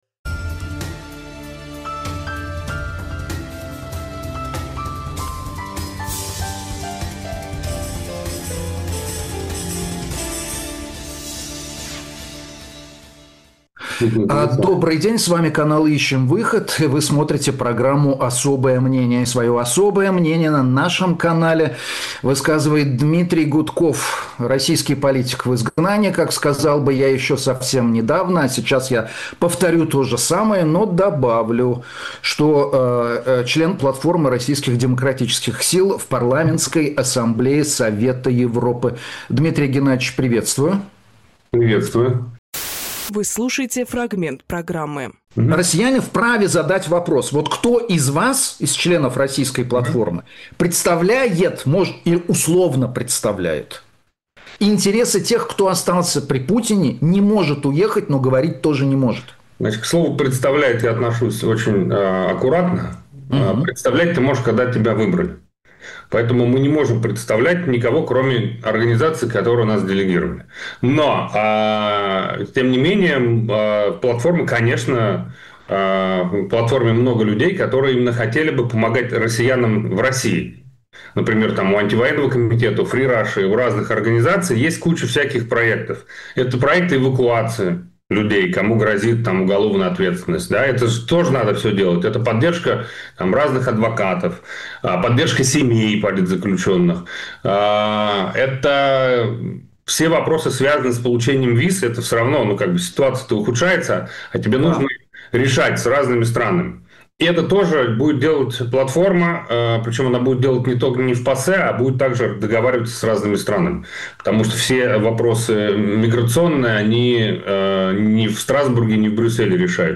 Дмитрий Гудковполитик
Дмитрий Губинжурналист
Фрагмент эфира от 02.02.26